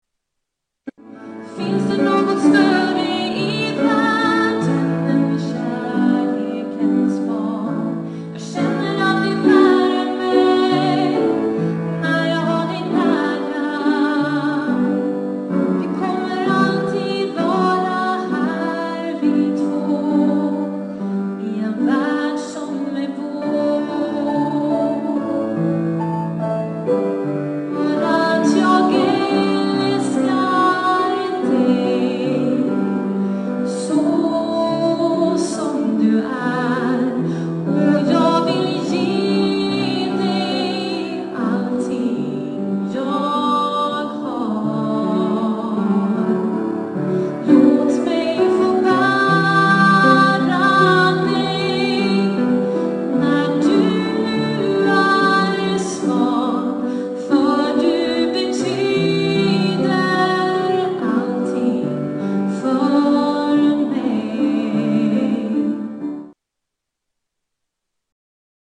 SJUNGA I KYRKAN | Backlights Partyband & Coverband
Tävelsås kyrka